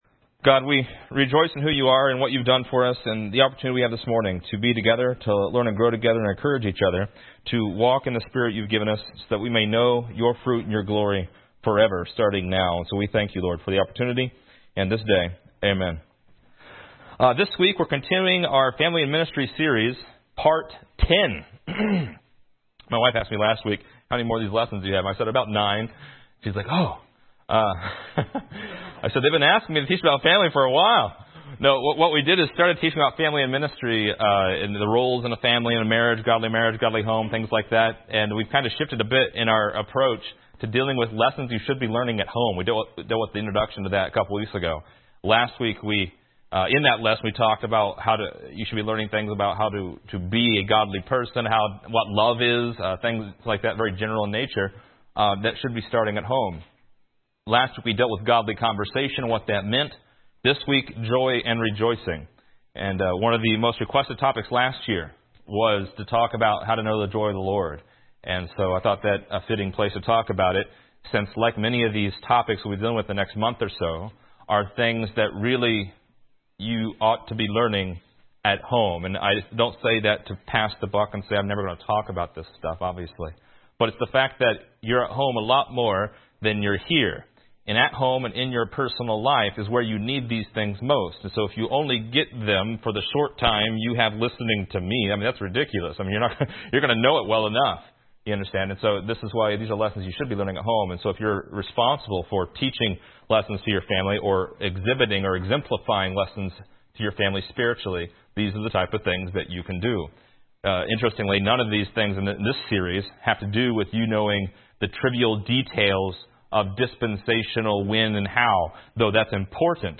Description: Our tenth lesson in this series about family and ministry continues a mini-series inside our overall series about lessons learned at home. How can you find joy and happiness in affliction?